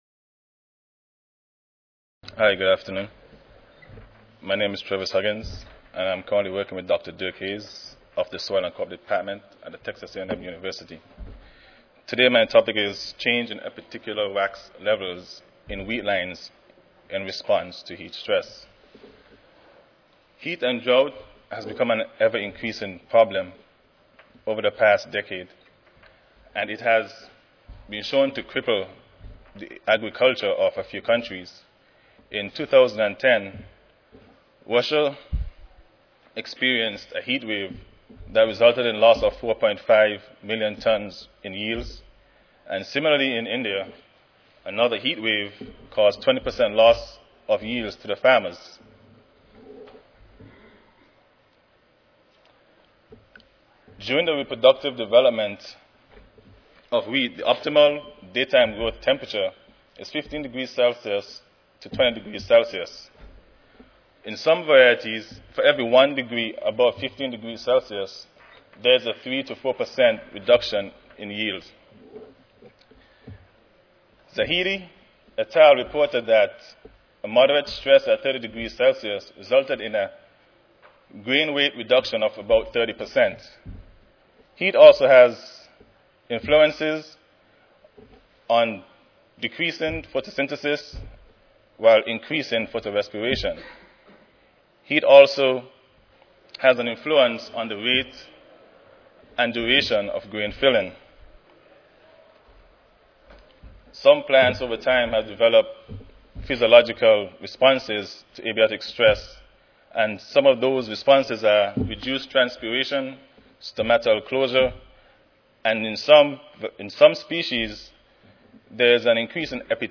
C01 Crop Breeding & Genetics Session: Crop Breeding and Genetics: Wheat (ASA, CSSA and SSSA Annual Meetings (San Antonio, TX - Oct. 16-19, 2011))
Texas A&M University Recorded Presentation Audio File